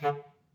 Clarinet
DCClar_stac_D2_v2_rr2_sum.wav